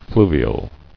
[flu·vi·al]